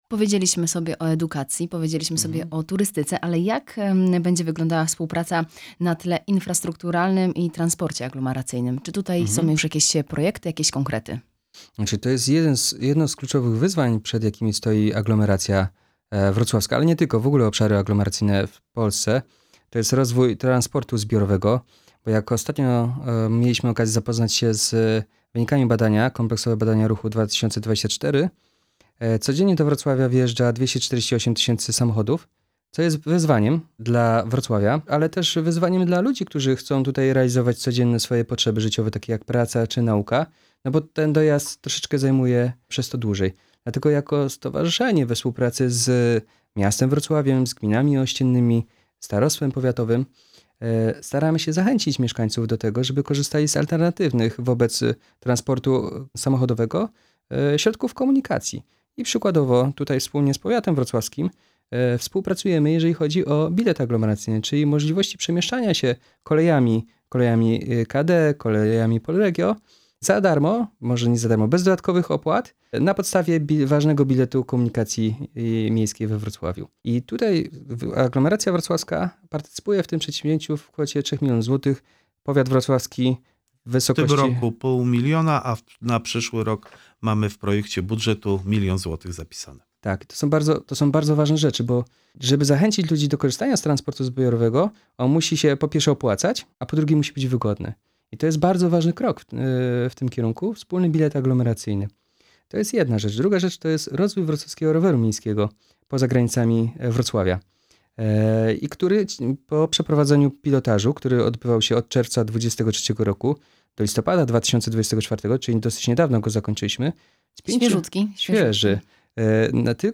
W studiu Radia Rodzina Włodzimierz Chlebosz
Cała rozmowa: